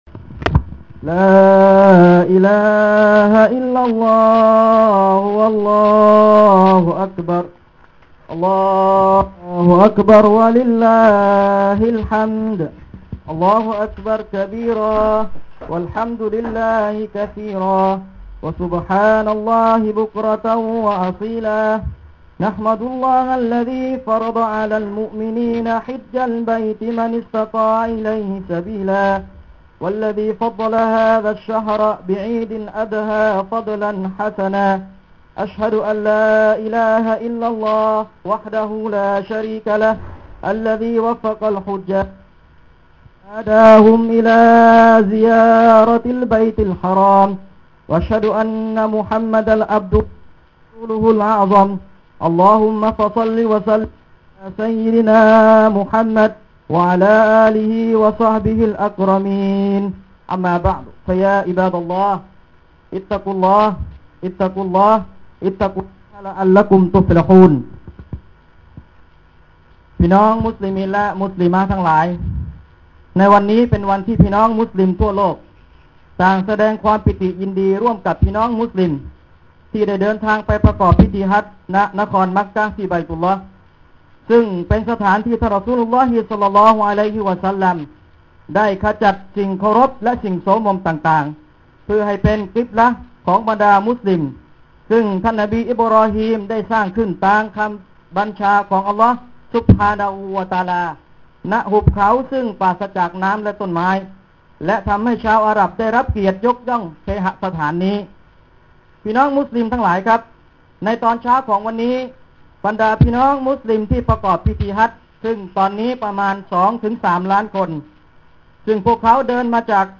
คุตบะห์วันอีดิ้ลอัฎฮา ฮ.ศ.1429
คุตบะห์วันอีดี้ลอัฎฮา มัสยิด อิกอมะตุ้ลมุอมินิน